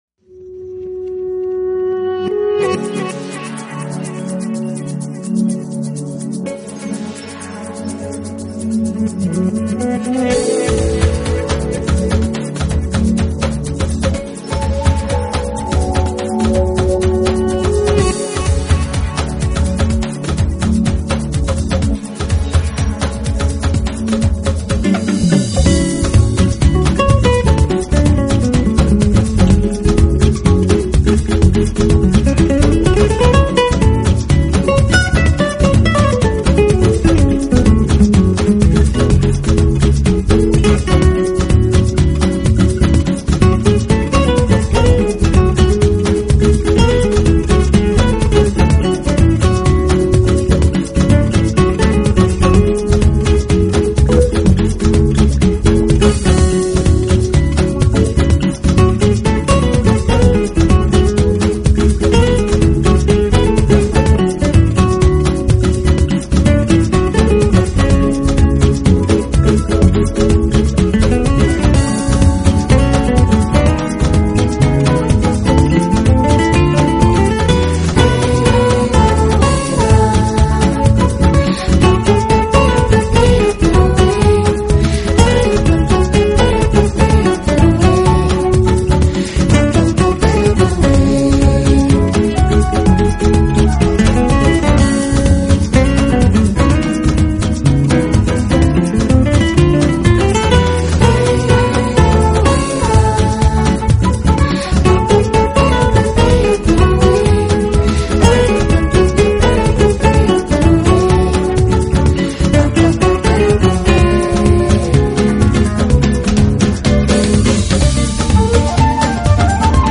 能够用一把木制Acoustic Guitar在Smooth Jazz领域闯天下的并不多，早年的